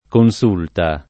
konS2lta] s. f. — con C- maiusc. il tribunale pontificio della Sacra C., il palazzo della C. costruito per esso in Roma (1732-34), e oggi la C., nome corrente (non uffic.) della Corte costituzionale, che in quel palazzo ha sede